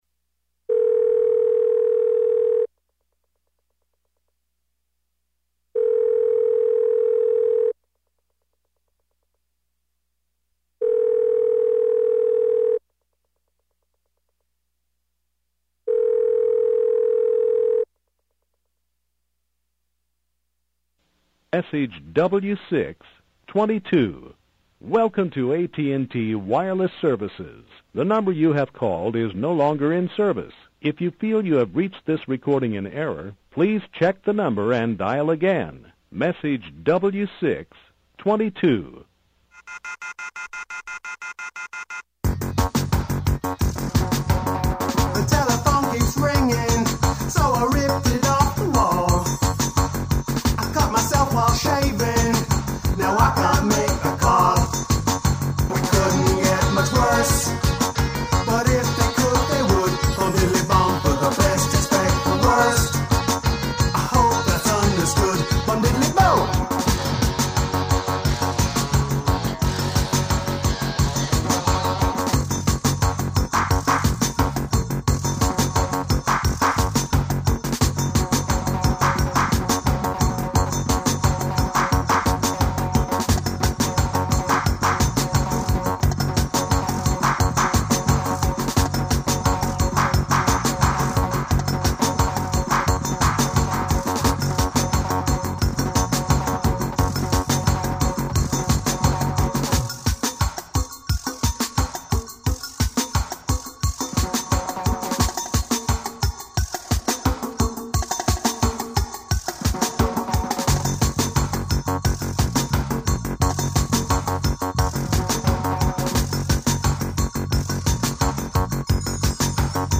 listener phone calls.